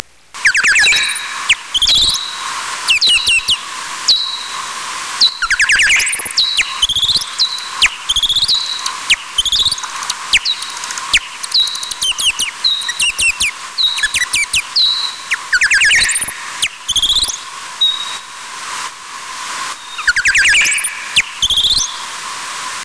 Echolokace a zvuková komunikace
Komunikační signály jsou mnohem složitější, často delší a také nižší, takže je slyší i lidé.
Většinou se dají popsat jako vřískání, skřípání, syčení, čiřikání, porouchaná zářivka vydávající vysoký tón.
Také můžete na podzim potkat osamělé netopýry – samce – kteří vábí samice svým zpěvem; v městech k nim patří zejména n.rezavý, n.pestrý a např. u hrází rybníků n.parkový (zde si poslechněte
vábení od samce ), n.hvízdavý a n.nejmenší.